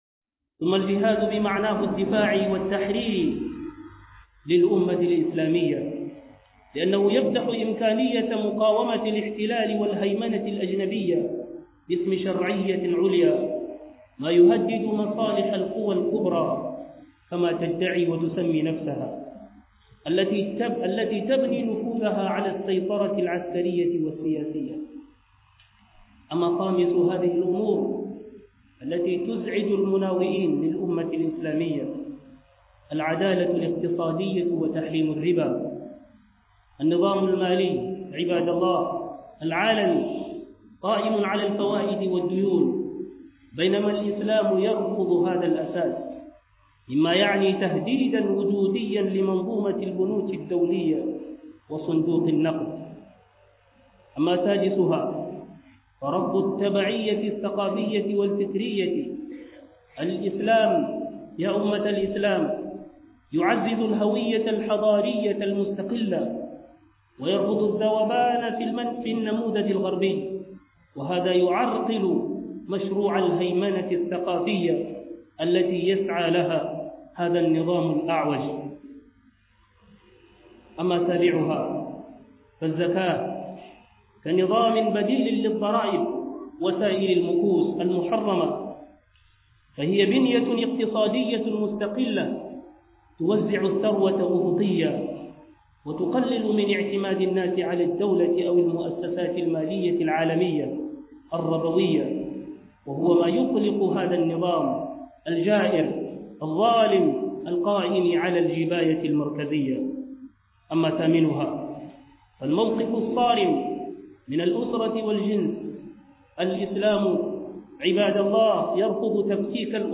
Me ke tsole mu su ido dangane da Musulunci_ - Huduba